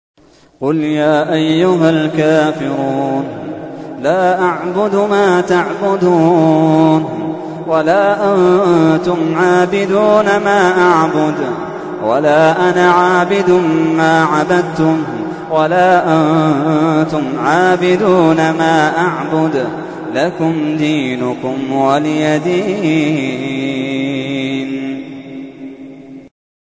تحميل : 109. سورة الكافرون / القارئ محمد اللحيدان / القرآن الكريم / موقع يا حسين